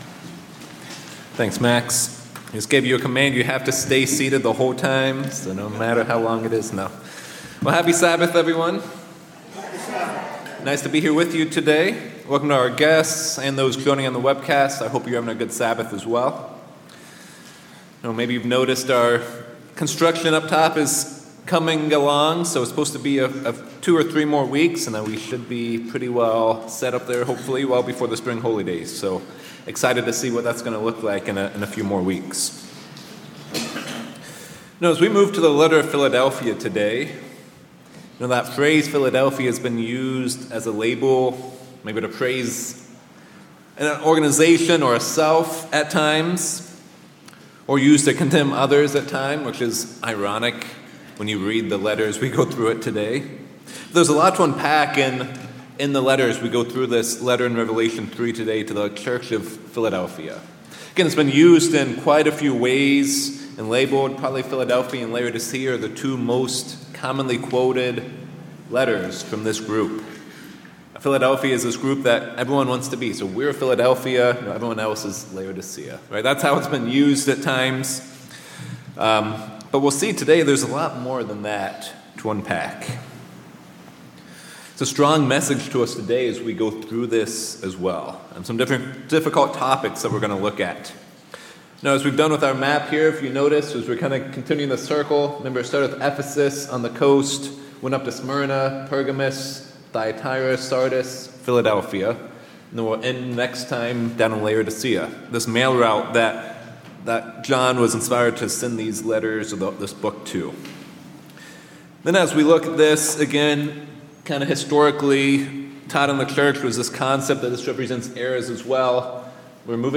In part six of the sermon series on the seven churches, we will read the letter to the church of Philadelphia and unpack the strong message it has for us today. This church is known as the faithful church and a model for obedience. They never denied God’s name even when persecuted.